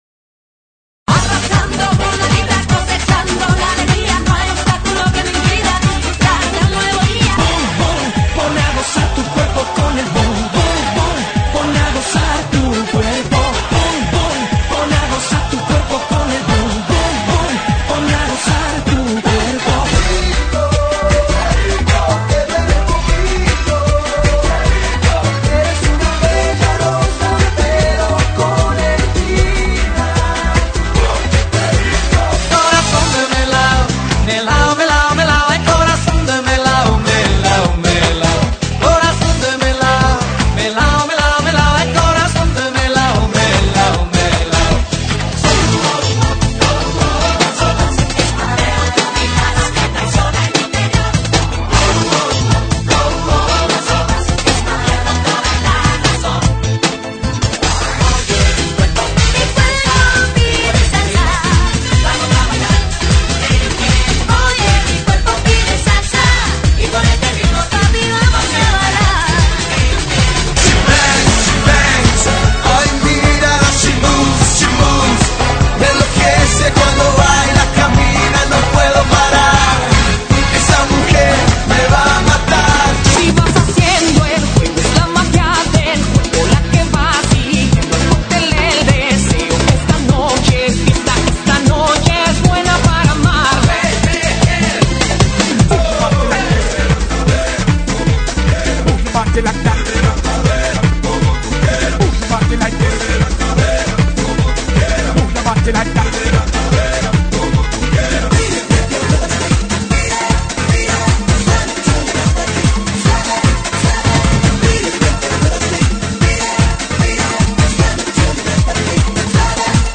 GENERO: LATINO – RADIO